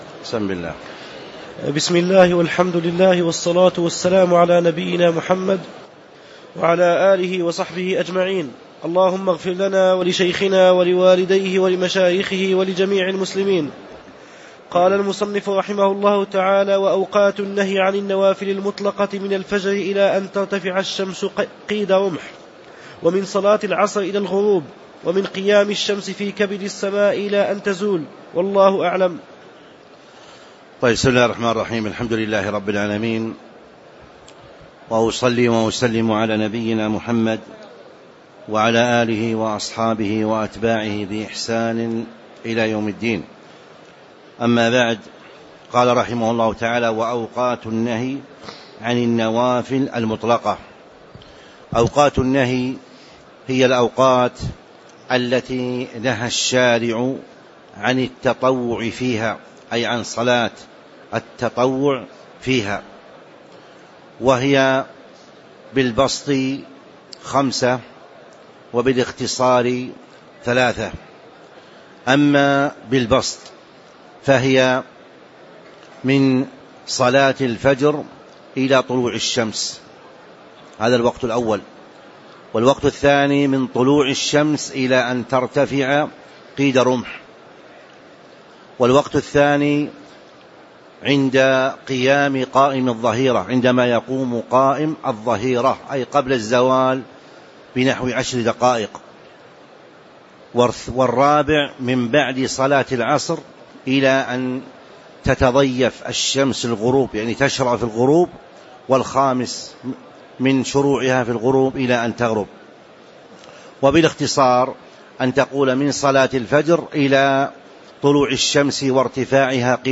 تاريخ النشر ٢٤ رمضان ١٤٤٥ هـ المكان: المسجد النبوي الشيخ